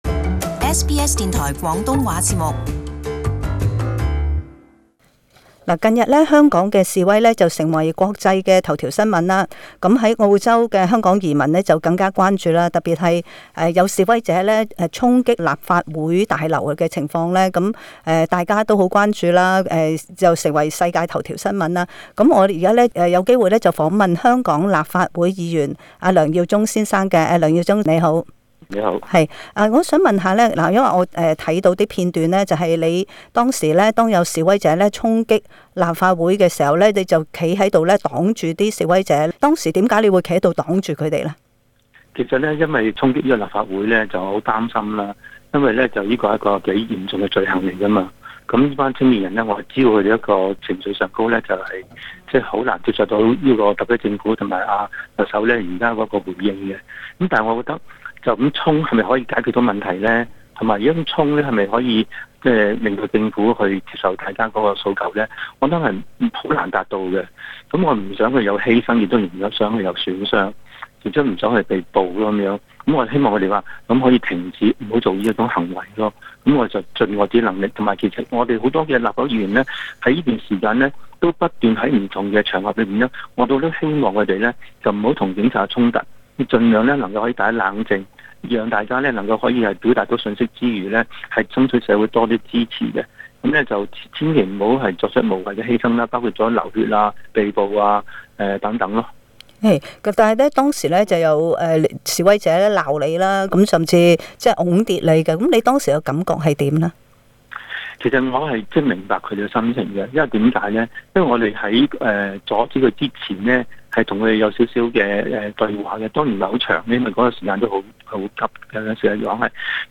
就香港示威者衝擊立法會大樓事件，本台專訪香港立法會議員梁耀忠，他當時曾經試圖阻擋示威者衝擊大樓，並遭示威者責罵以及推跌，他在訪問中表示明白示威者的激動的情緒。